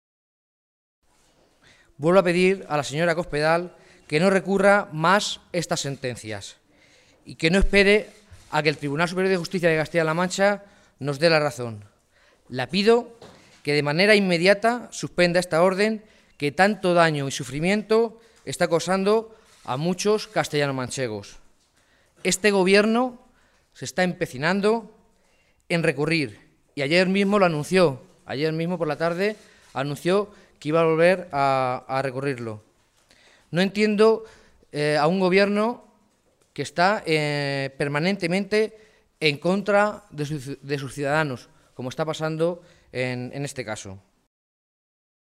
Jesús Fernández Clemente, alcalde de Tembleque
Cortes de audio de la rueda de prensa